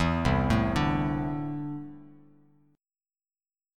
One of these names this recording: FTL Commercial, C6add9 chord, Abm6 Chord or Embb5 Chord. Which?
C6add9 chord